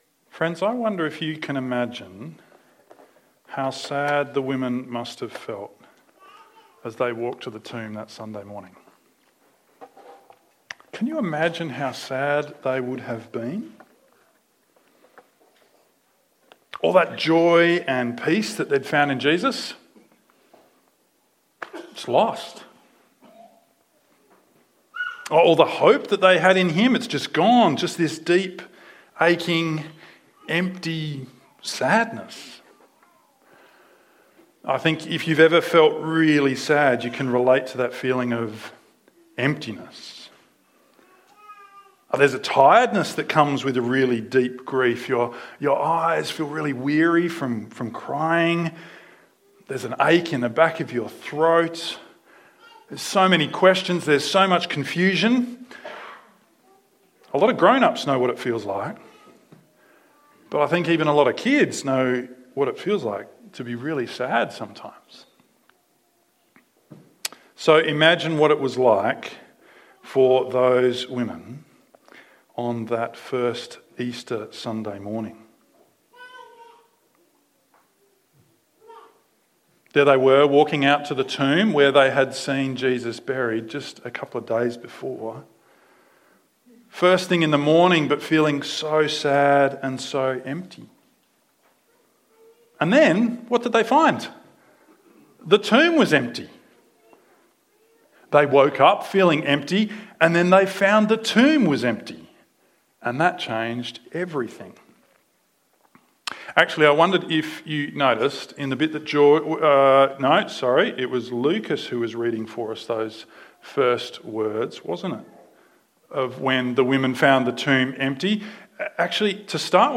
24:12 Share this sermon: